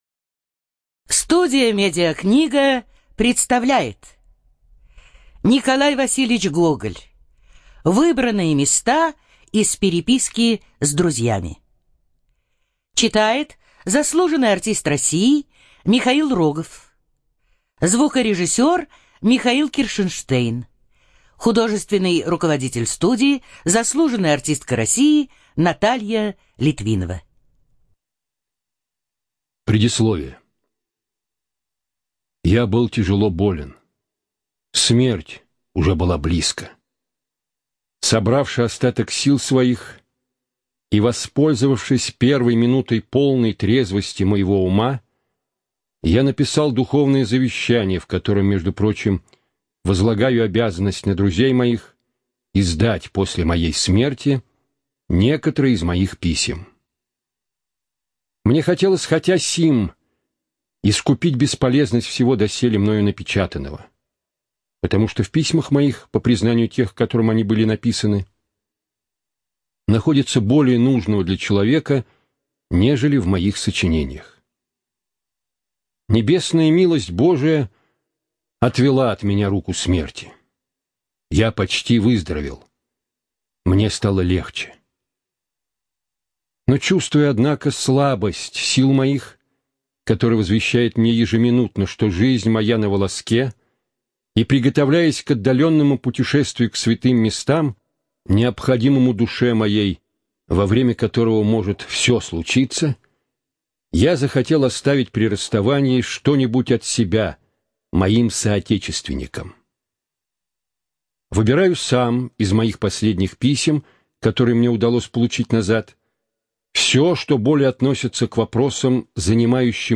ЖанрБиографии и мемуары
Студия звукозаписиМедиакнига